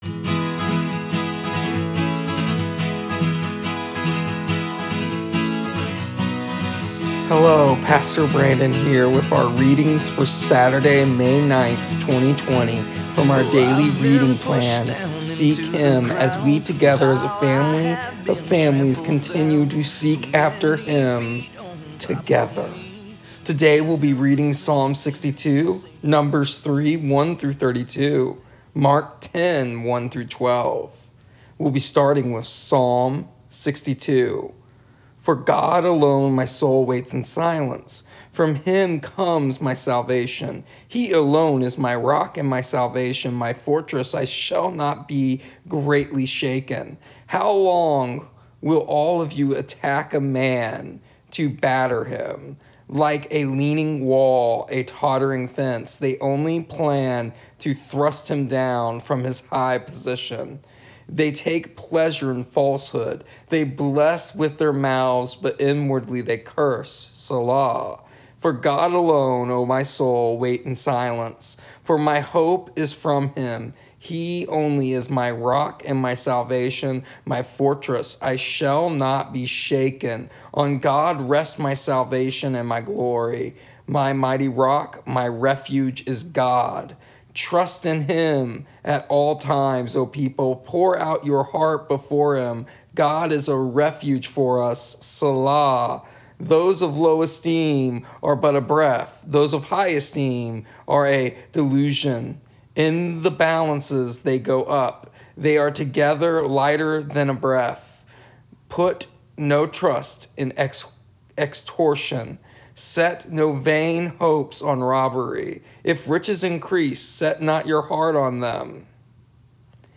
Here is our daily readings in an audio version for May 9th, 2020. Today we also discuss the concept of being shaken and how easy it is for us to become shaken dispute our knowing of God, and how this should be the opposite. Giving us the challenge of trusting in who God is and what God has done and what God has yet to do.